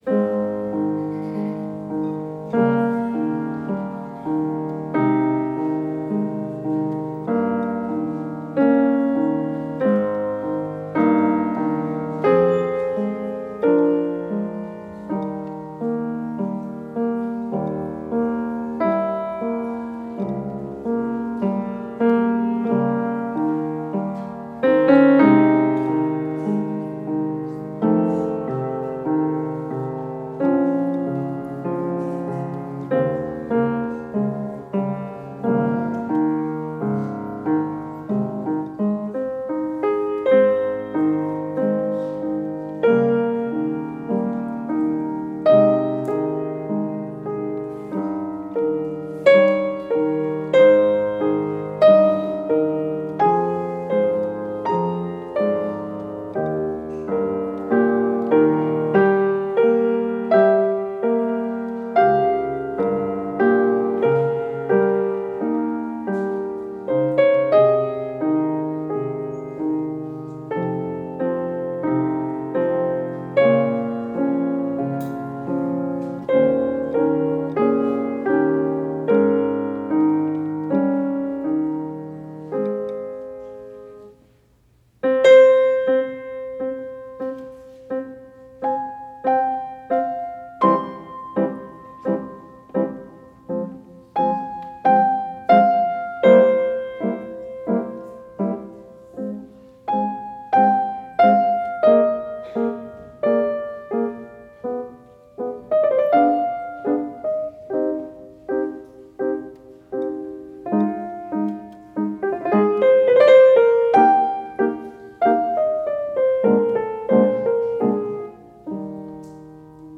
Seul face à son piano, le compositeur Américain nous embarquait pour un nouveau moment musical classique en 3 mouvements dont ce Adagio cantabile !